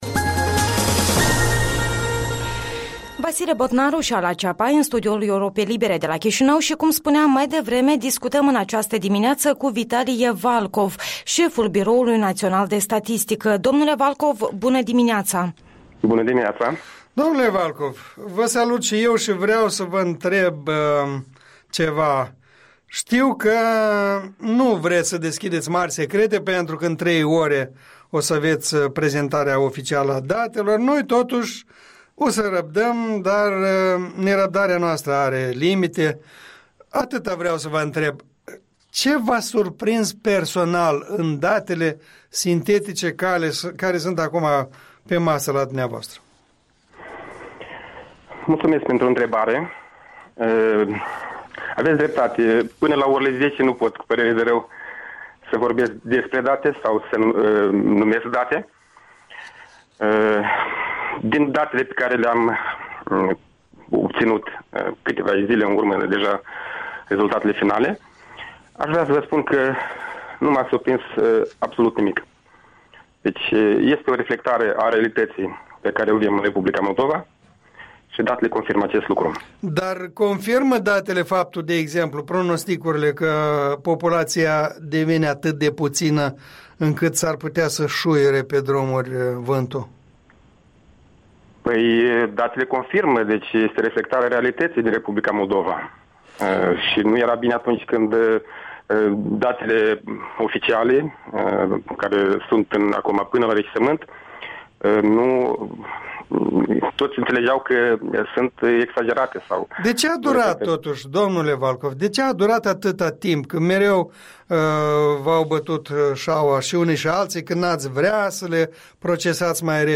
Interviul dimineții la EL: cu Vitalie Valcov